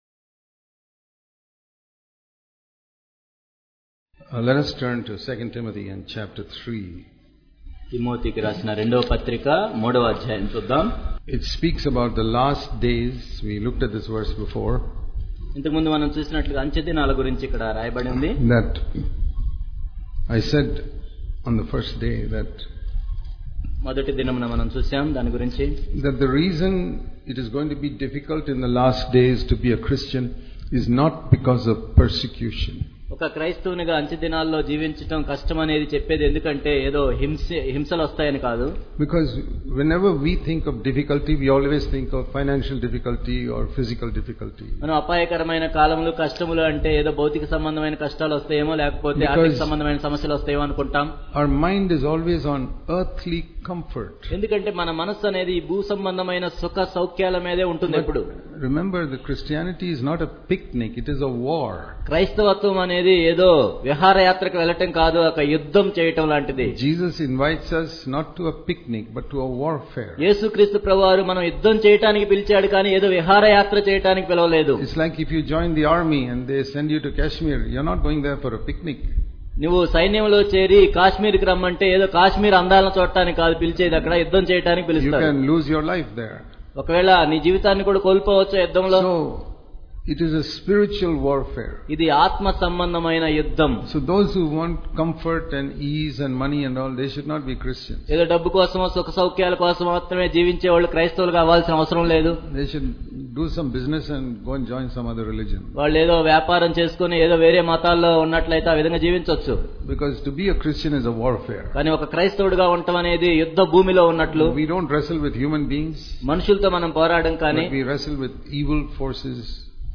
Overcoming Satan And Pursuing Godliness What Is True Christianity - Hyderabad Conference 2017
Overcoming Satan And Pursuing Godliness What Is True Christianity - Hyderabad Conference 2017 Hyderabad Conference 2017 Click here to View All Sermons